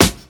• '00s Hip-Hop Snare F Key 32.wav
Royality free snare drum sound tuned to the F note.
00s-hip-hop-snare-f-key-32-vU4.wav